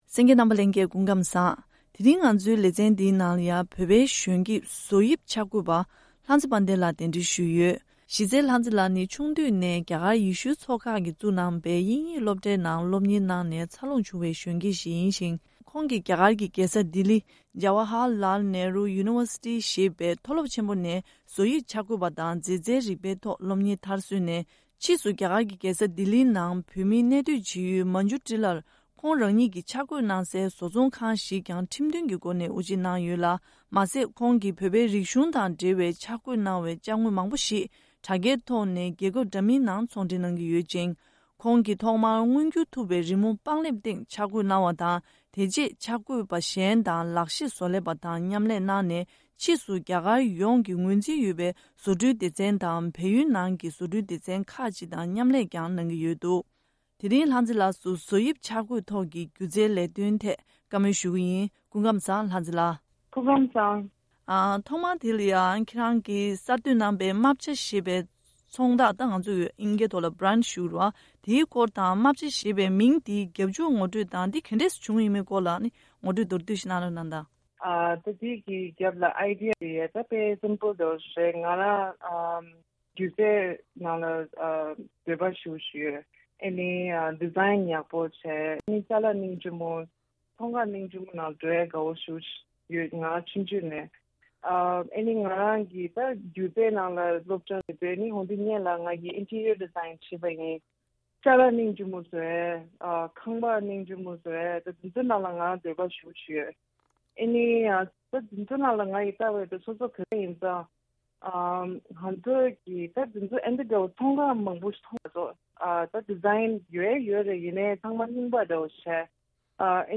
ཕྱོགས་བསྡུས་ཞུས་པའི་གནས་ཚུལ།